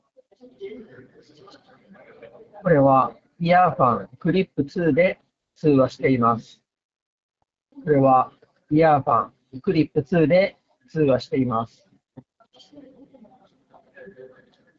4マイク搭載のAI ENCノイズキャンセリングの性能を実際に確認しました。
周囲に喧騒音を流した状態で通話を録音して検証しています。
通話時のノイズキャンセリング性能は優秀で、周囲の雑音をしっかり抑えてくれます。
音声はクリアで聞き取りやすく、若干ボワッとした感じはあるものの、通常の音声通話はもちろんWeb会議にも普通に使えるレベルだと思います。
earfun-clip-2-voice.m4a